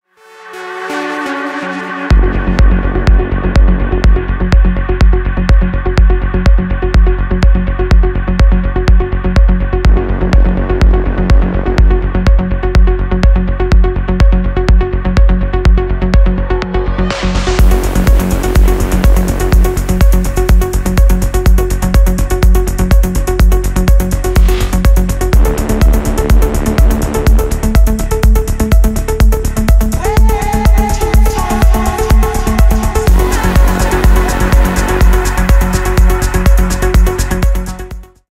Ремикс # Поп Музыка
клубные